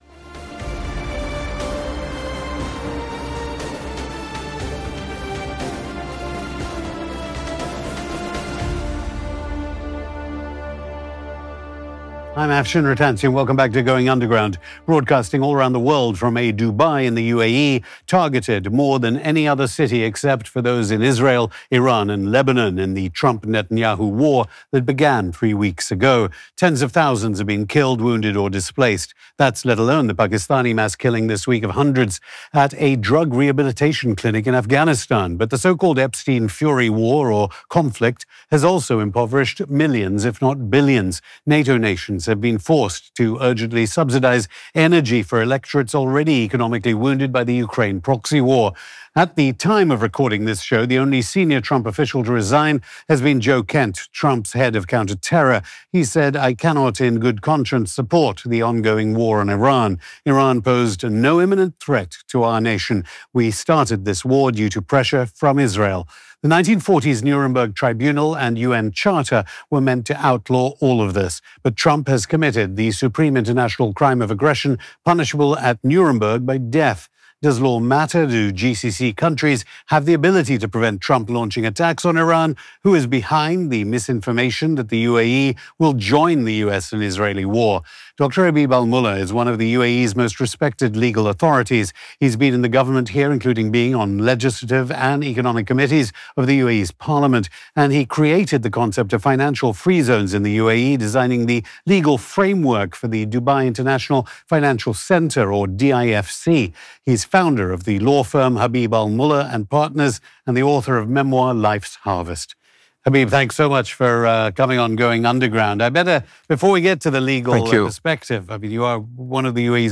Going Underground Hosted by Afshin Rattansi